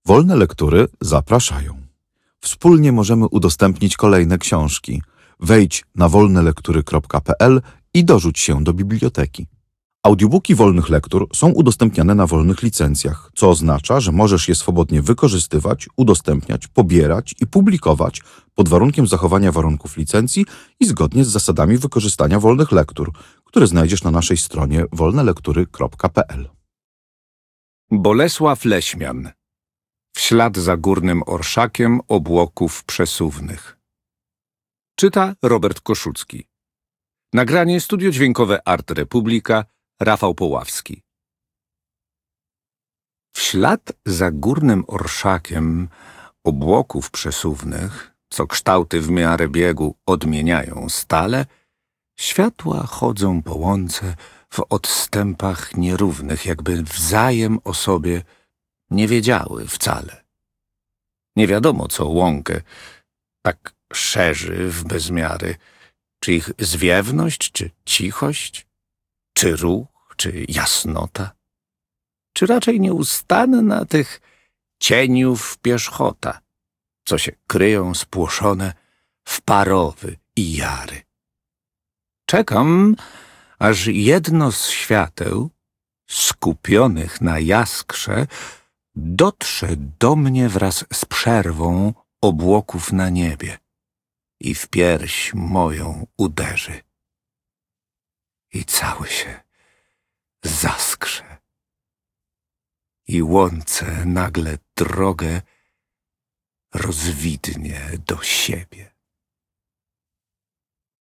Wiersz
Audiobook